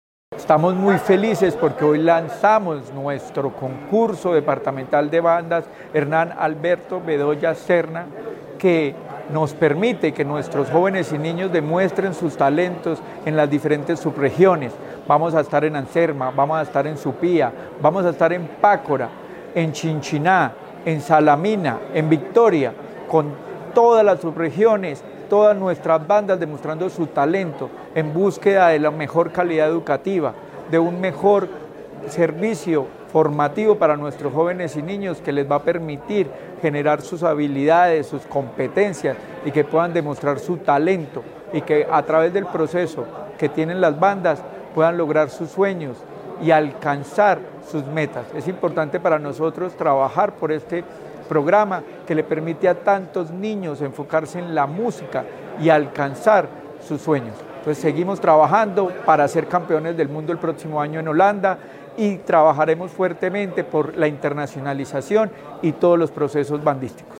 Secretario de Educación de Caldas, Luis Herney Vargas Barrera.